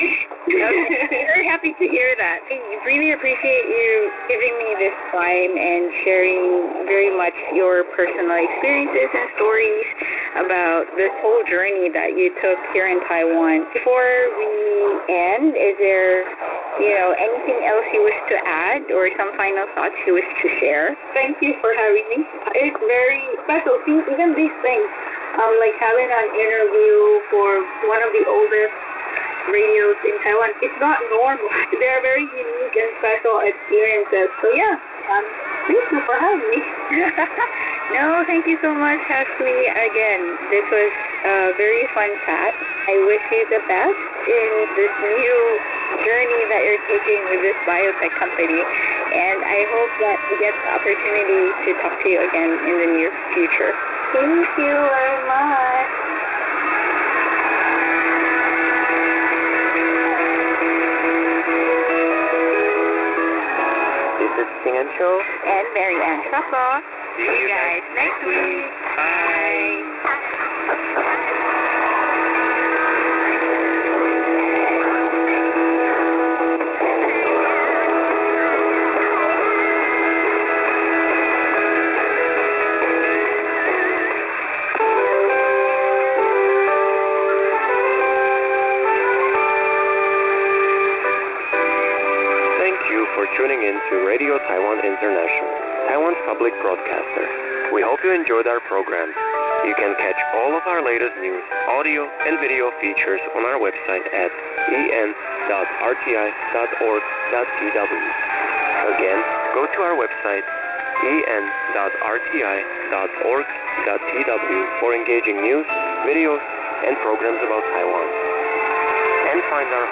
Today, Radio Taiwan International continues to offer programs on shortwave in a number of languages from a transmitter in Taiwan, though there appears to be only one English-language broadcast per day, that being from 1600 to 1700 hours UTC on 9405 kHz. Here is a recording of the station signing on at 1600 UTC on January 1, 2026 using a remote SDR located in Japan: